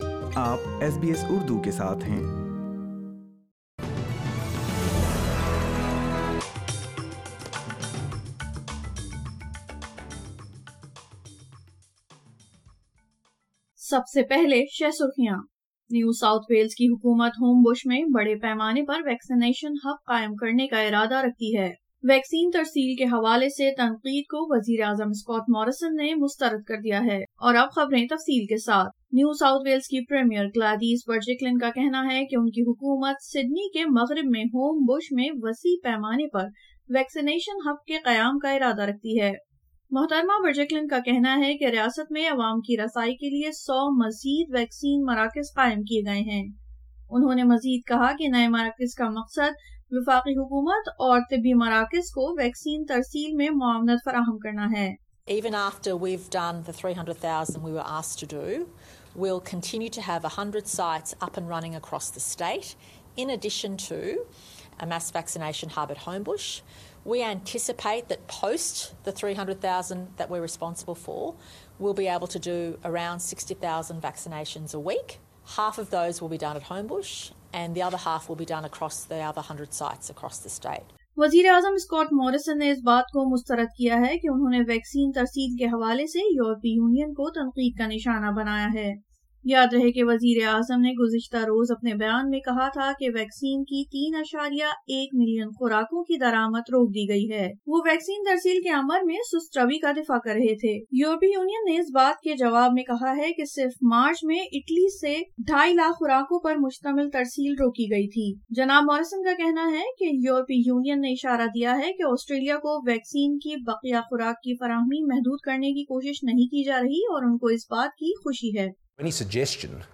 اردو خبریں 07 اپریل 2021